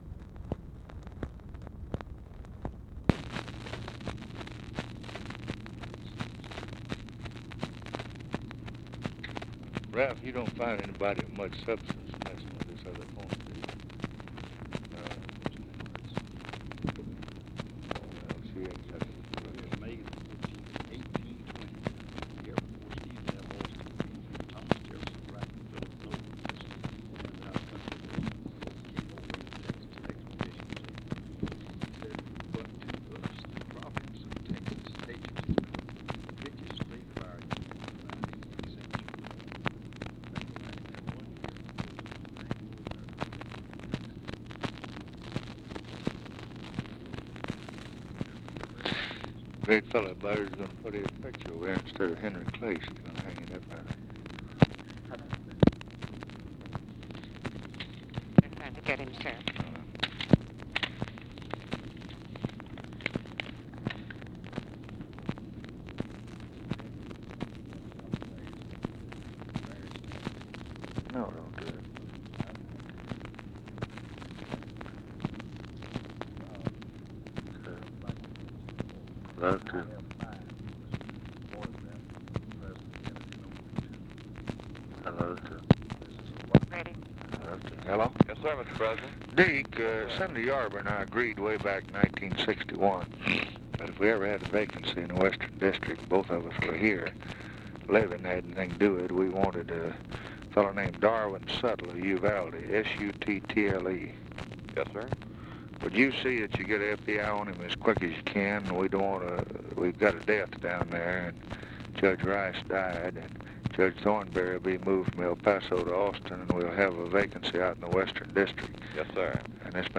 Conversation with CARTHA DELOACH and OFFICE CONVERSATION, March 19, 1964
Secret White House Tapes